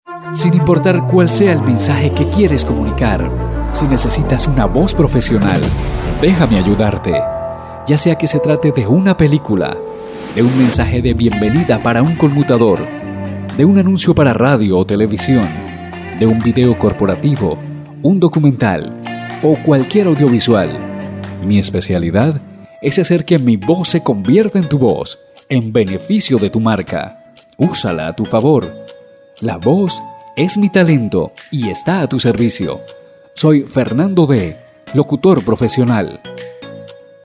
Professional voice for recording Videos for television, youtube and other social networks Professional voice for educational and explanatory content elearning Professional voice for radio and television commercials Professional Voice for PBX - IVR Telephone or Audio response systems Professional audio recording and editing
Sprechprobe: Sonstiges (Muttersprache):
Demo para video corporativo documental pelicula 1.mp3